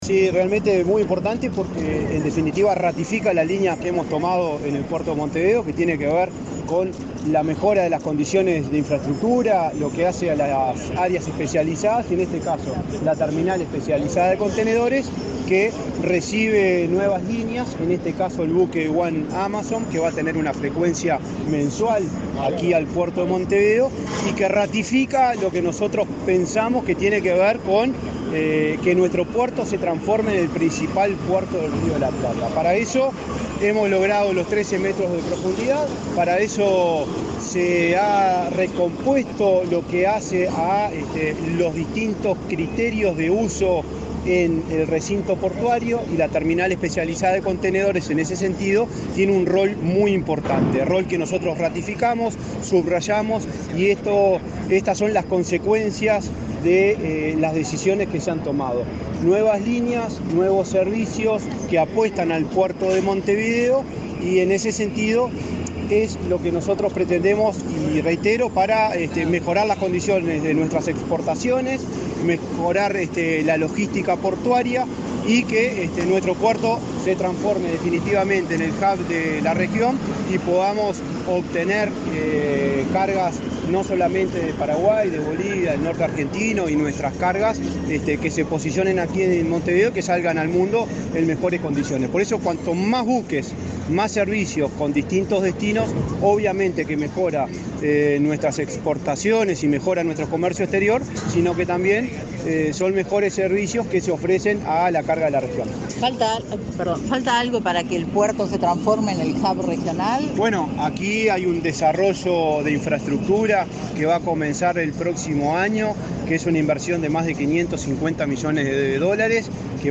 Declaraciones del presidente de la ANP, Juan Curbelo
El presidente de la Administración Nacional de Puertos (ANP), Juan Curbelo, dialogó con la prensa sobre la llegada al puerto de Montevideo del buque